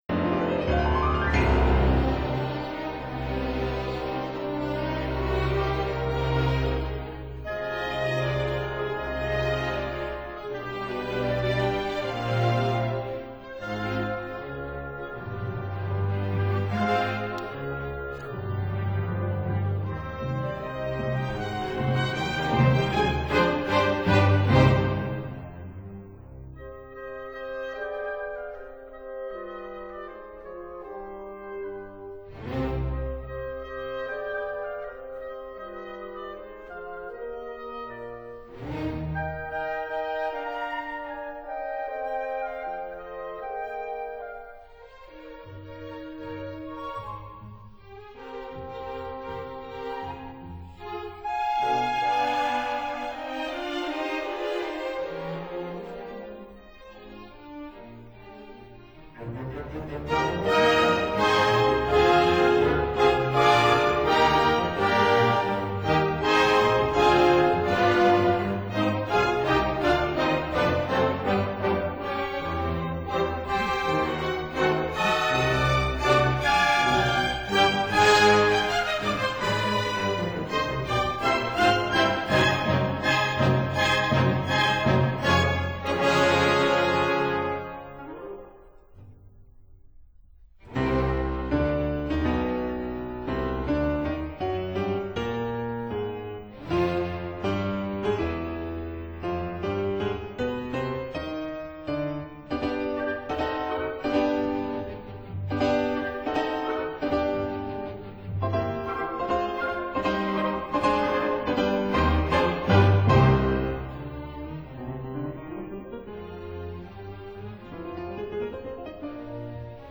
•(04) Suite Concertante for cello, piano & orchestra
piano & conductor